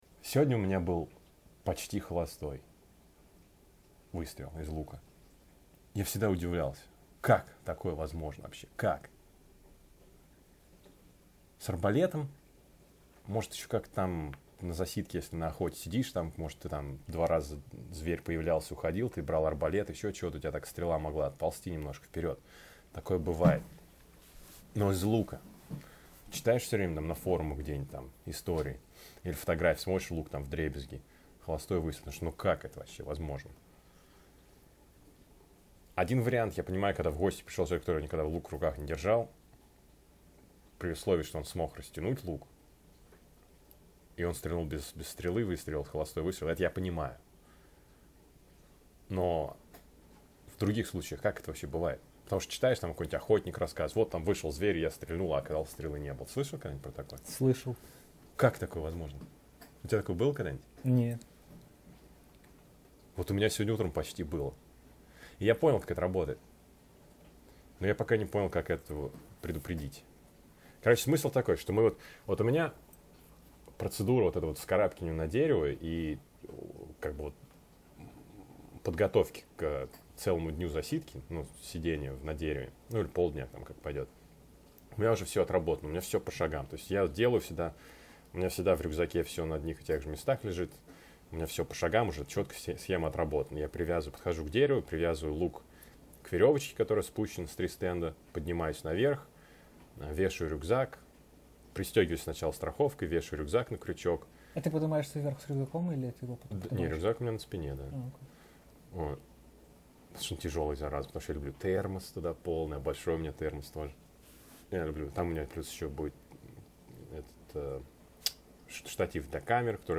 Он меня угостил крепким кофе, и мы обсудили несколько интересных тем.